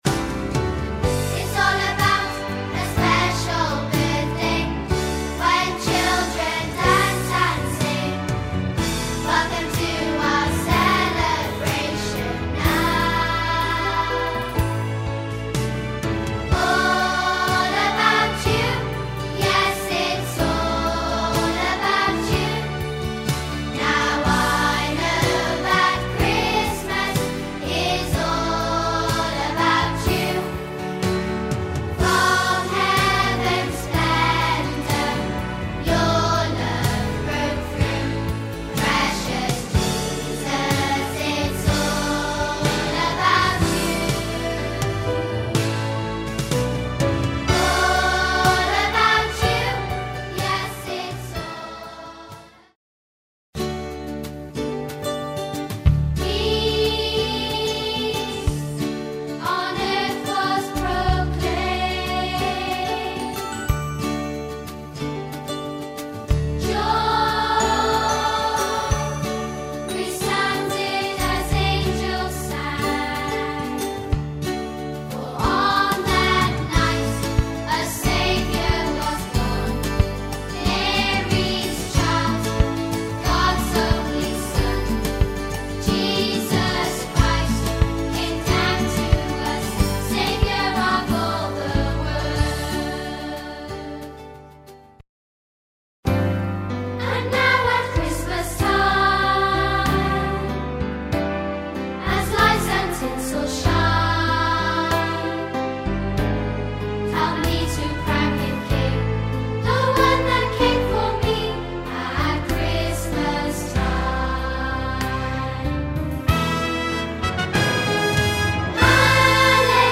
A collection of 12 festive songs for children
up-tempo Motown beat
thought-provoking ballads